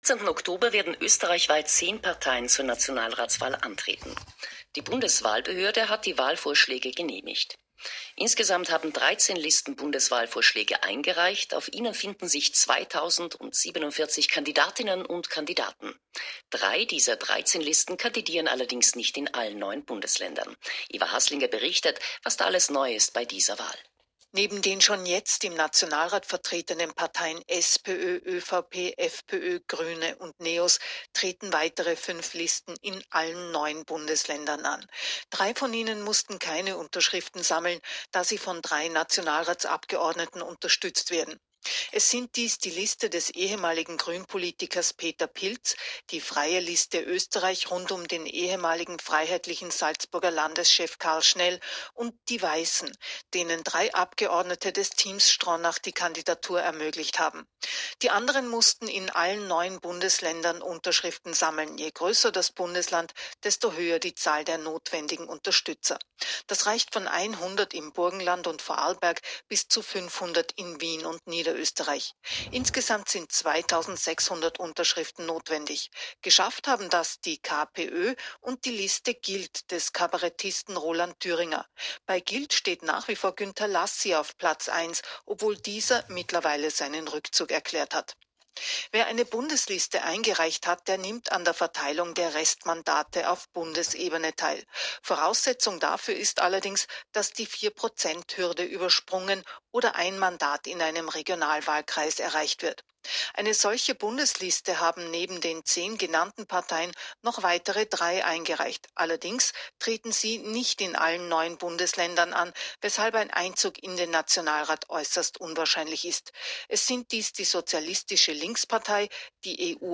Dazu auch BMI Sektionschef Dr. Vogl im ORF Morgenjournal am 1. September 2017
oe1-fruehjournal-vogl-interview.wav